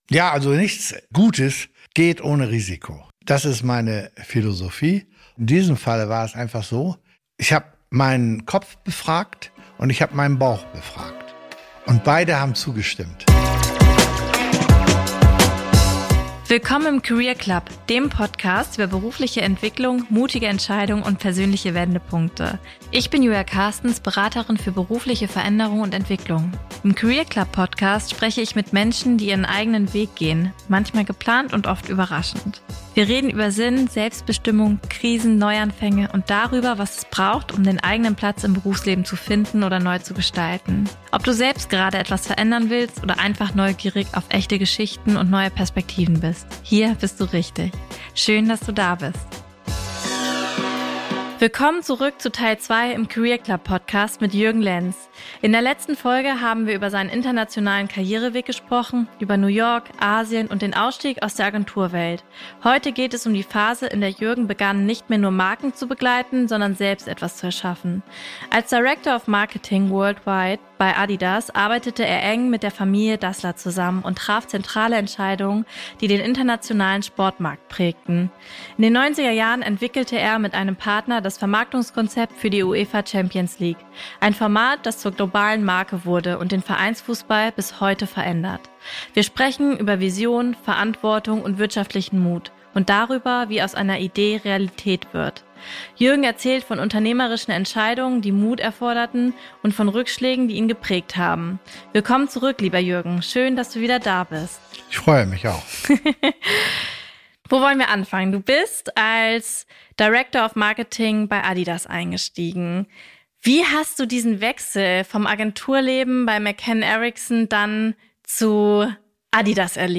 Ein Gespräch über Haltung, Unternehmertum und darüber, was bleibt, wenn das Sichtbare abgeschlossen ist.